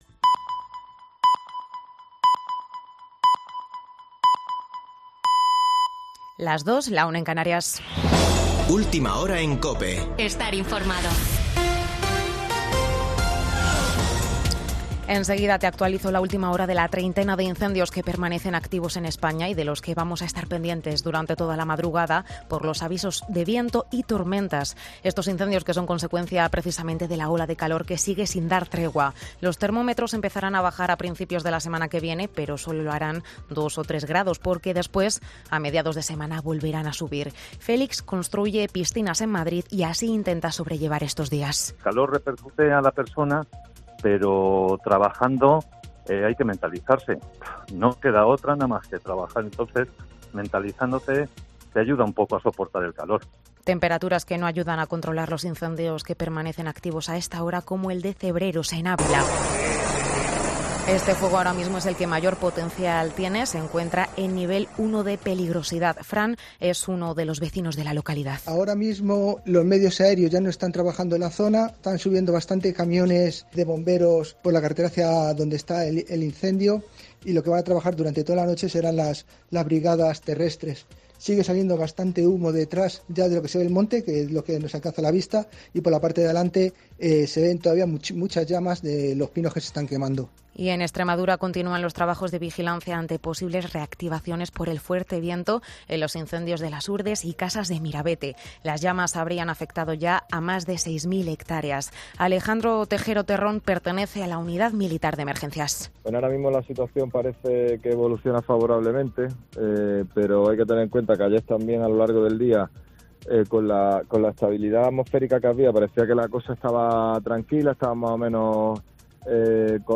Boletín de noticias de COPE del 17 de julio de 2022 a las 02:00 horas